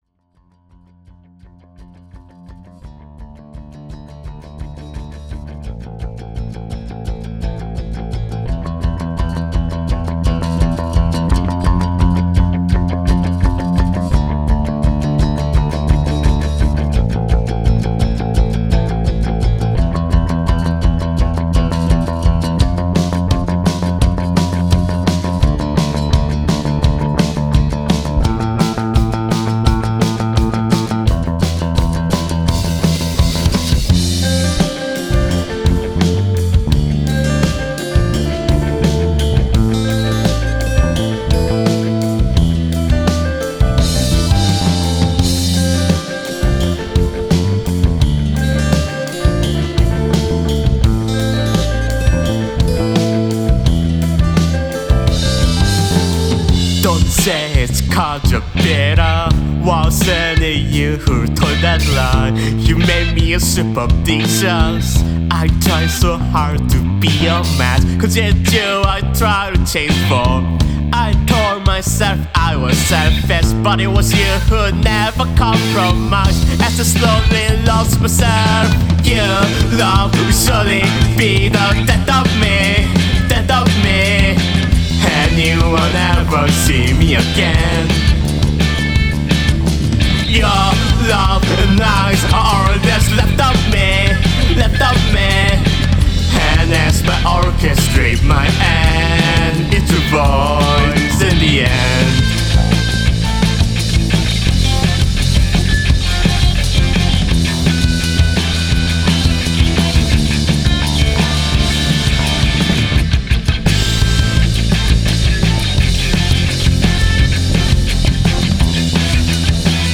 It's an emo album about being a sad bunny.
Guitar, bass, bg vocals